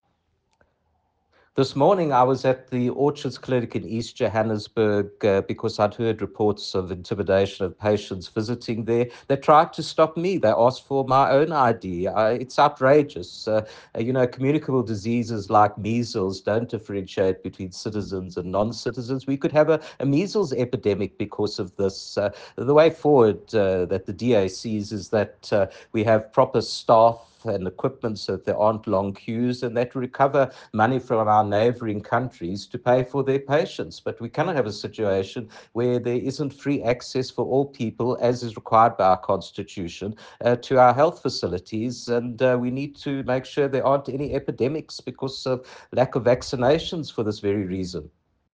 soundbite by Dr Jack Bloom MPL.